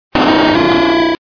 Cri de Noarfang dans Pokémon Diamant et Perle.